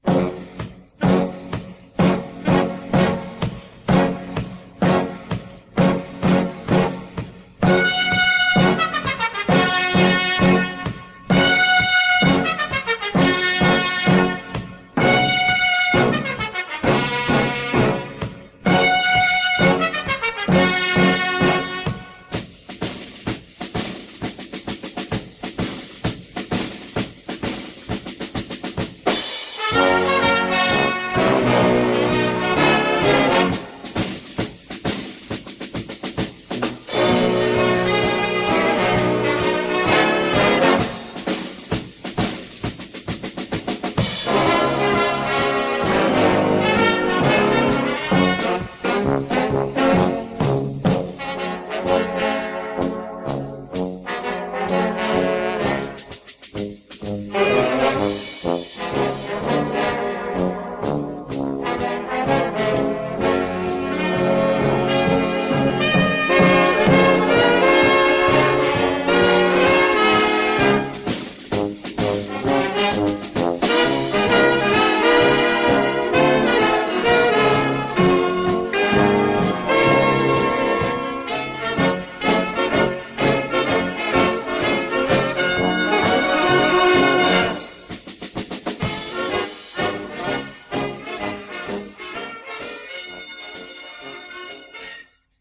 Gattung: Potpourri
Besetzung: Blasorchester
Big-Band Arrangement